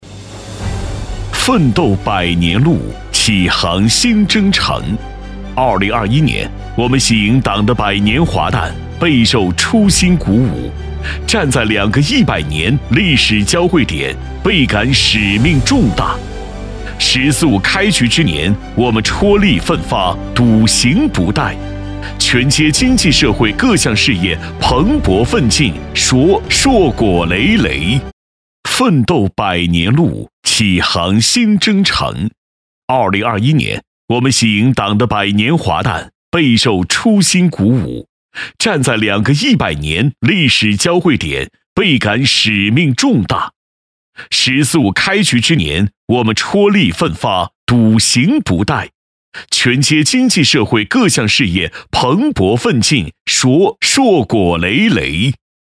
淘声配音网，专题，宣传片配音，专业网络配音平台